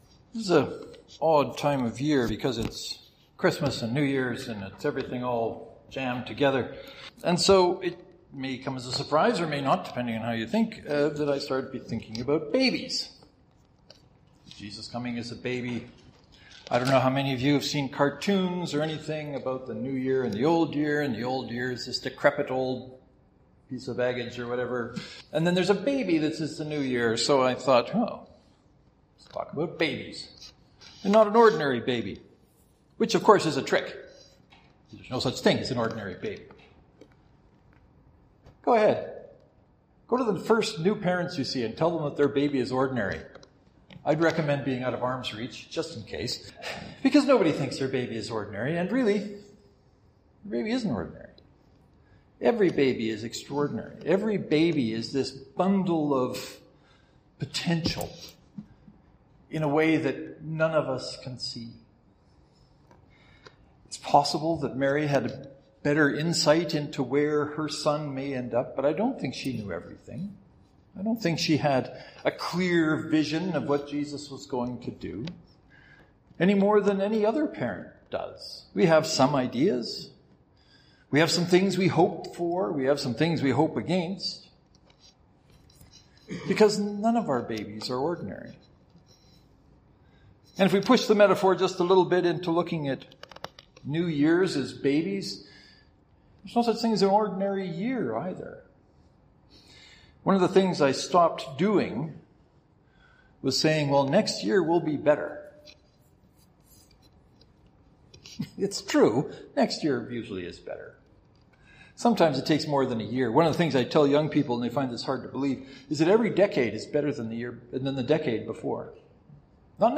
As mentioned in the sermon, go to new parents and tell them their baby is “ordinary.”